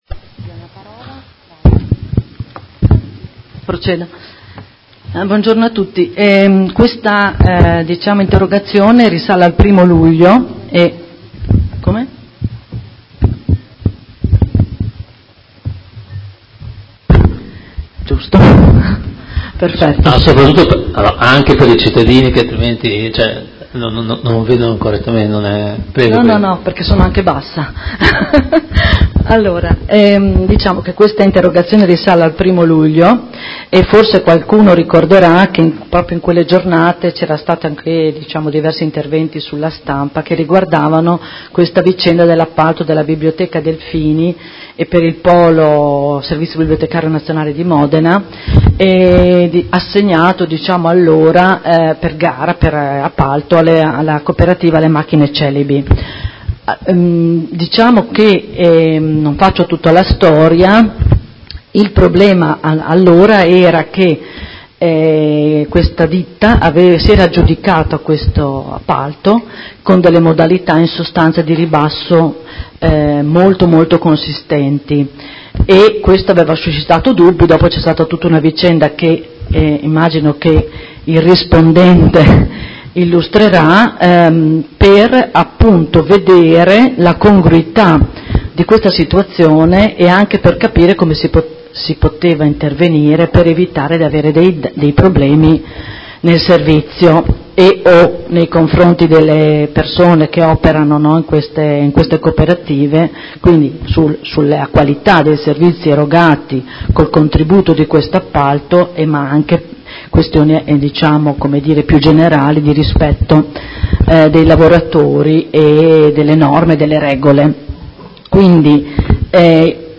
Enrica Manenti — Sito Audio Consiglio Comunale